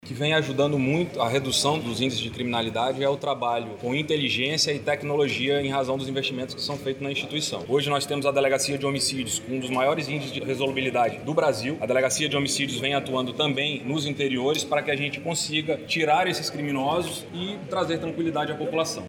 O delegado-geral da Polícia Civil do Amazonas, Bruno Fraga, destaca os investimentos em tecnologia como fator principal para a redução dos índices.